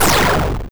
Explosion4.wav